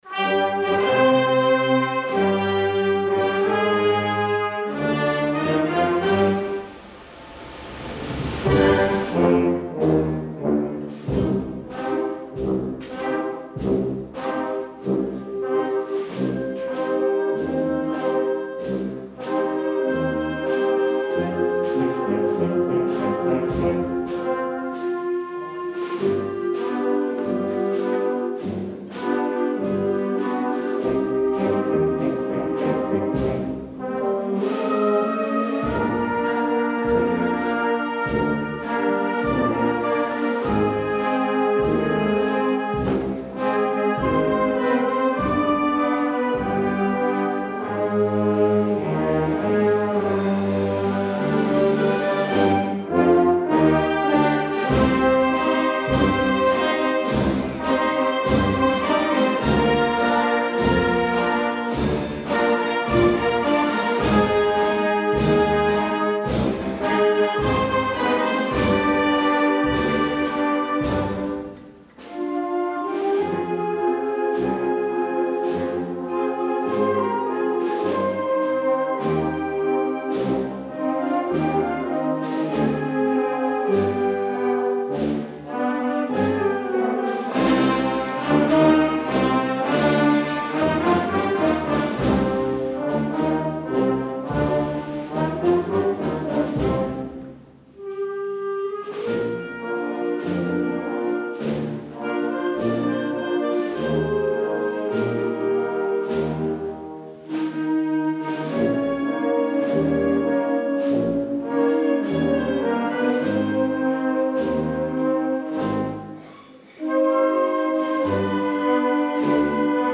Concert de Setmana Santa. Auditori de Porreres.